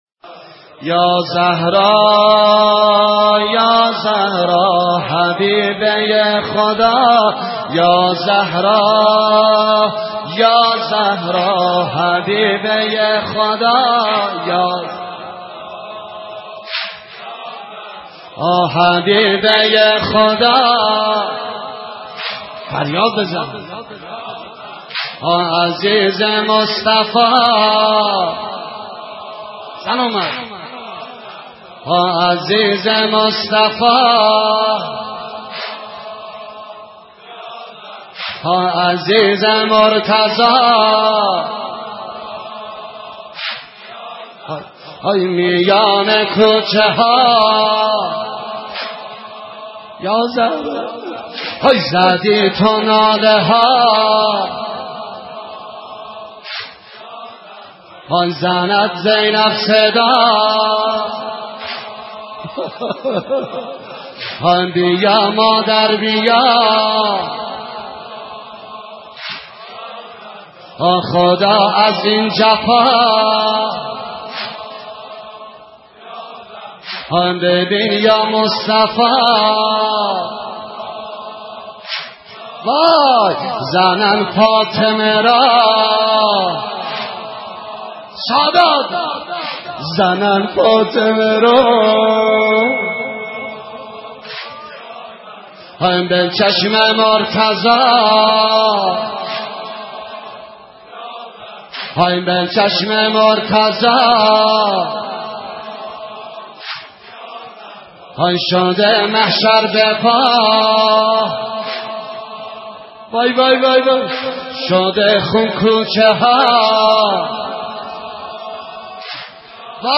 مرثیه خوانی